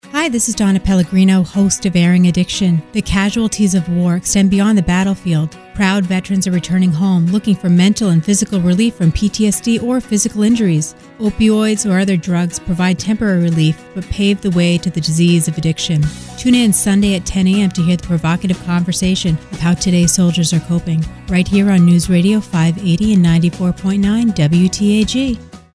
Airing_Addiction_Todays_Soldier_promo_for_Sun_Nov_13-16.mp3